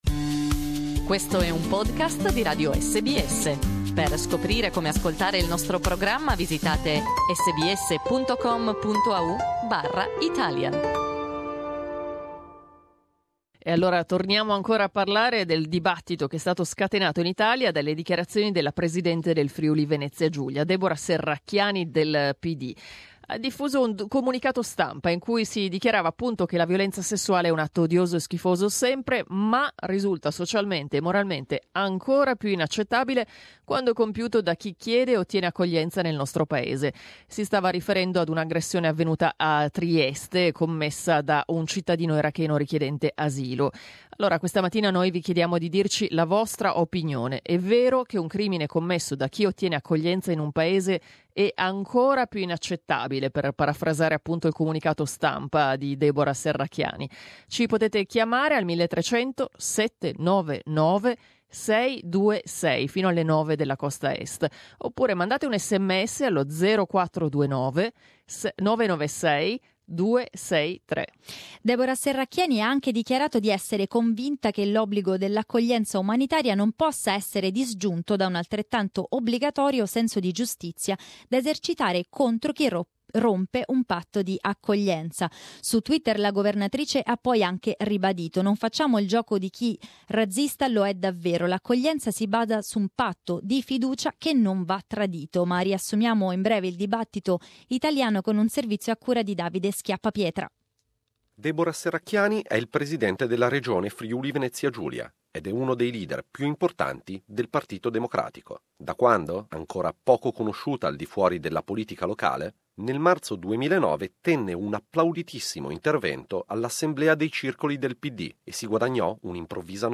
We talked about it with our listeners.